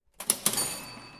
Kasse.mp3